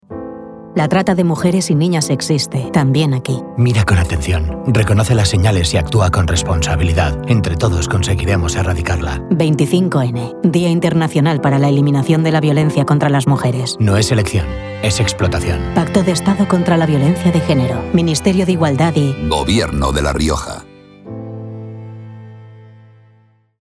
Cuñas radiofónicas